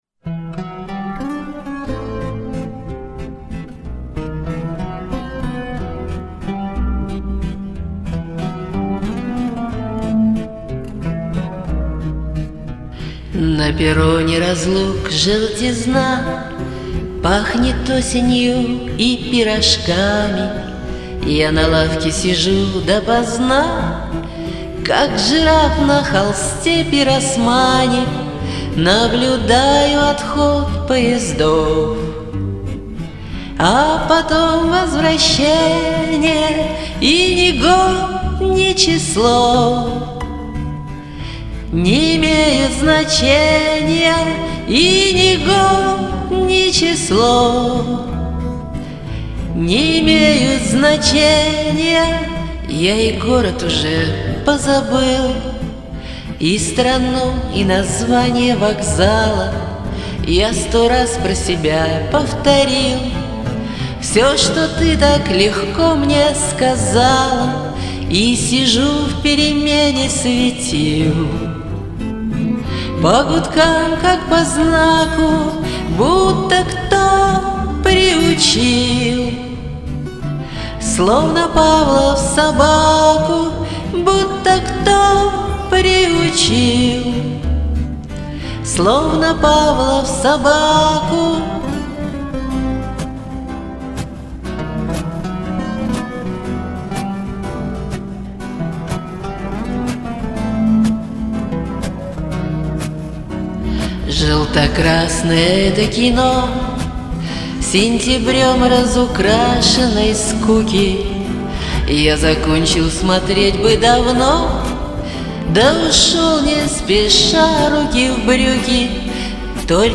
более бардовская версия